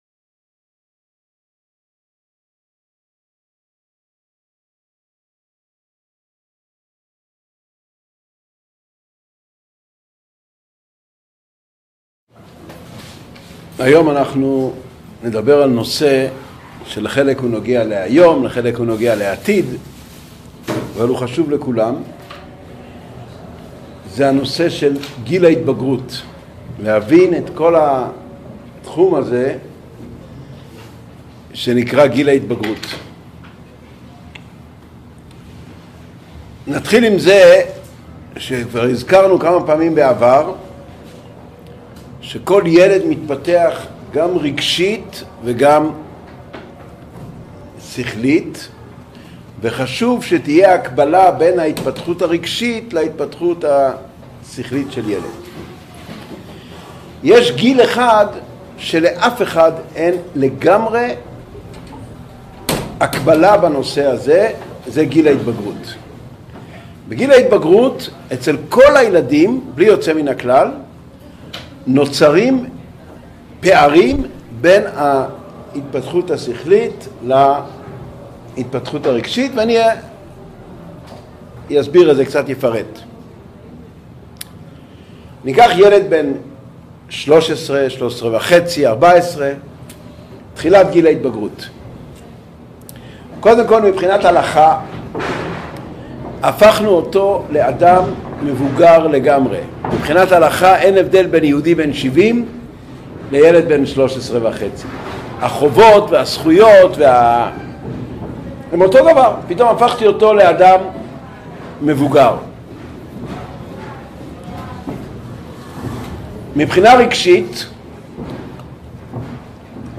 Урок № 4.